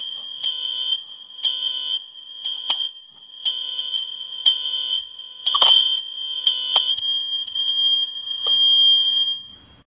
Снова пожарная тревога
firealarm2.wav